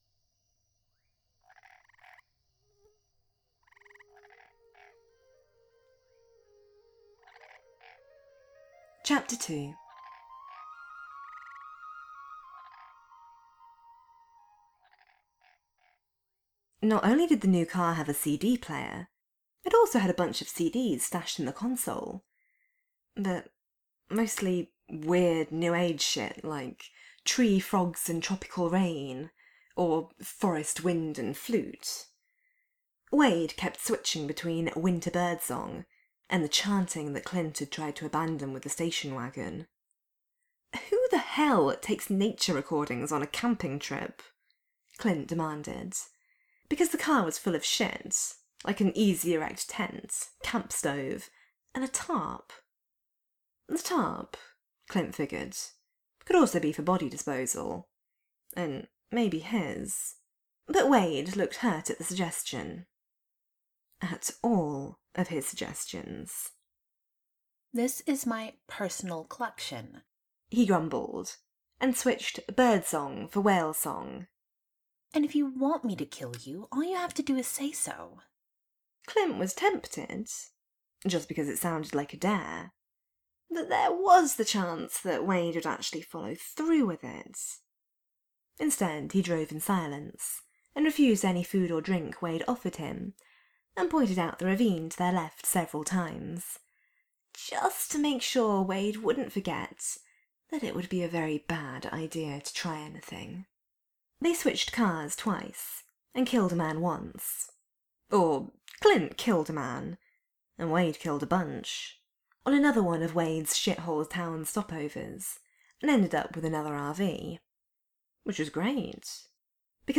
This is a multi-voice podfic, so I am posting on behalf of both of us. This fic is the best, and mixing a range of croaking frogs and wooden flutes was the highlight of my month. Summary: In which Deadpool kidnaps Clint off a battlefield and takes him on a roadtrip.